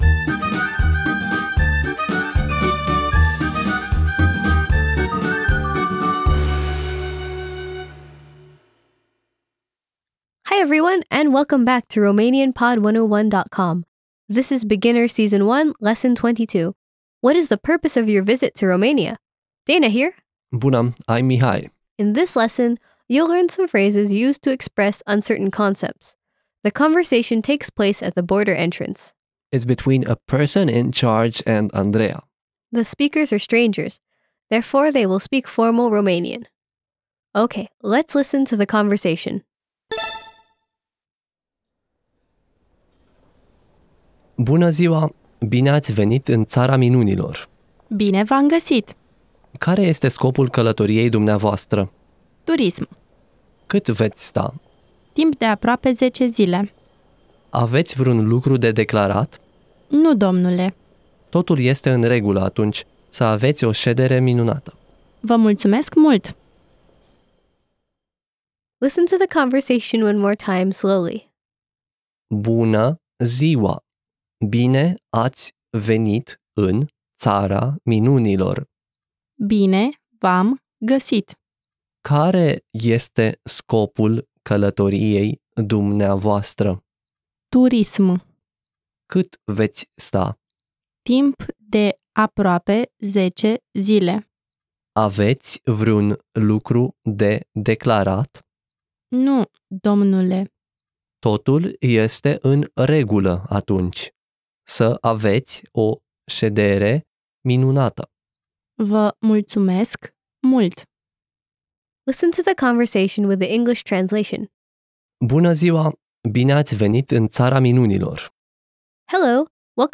1 College & Cocktails: Canadian Excellence Meet 9:53 Play Pause 1h ago 9:53 Play Pause Play later Play later Lists Like Liked 9:53 College & Cocktails: Iowa and Minnesota at Utah College gymnastics is back, and College & Cocktails returns with its live post-meet breakdown of the Canadian Excellence showdown featuring Utah, Minnesota, and Iowa. Recorded immediately after the meet, this episode covers early-season judging trends, start value controversies, standout performances,…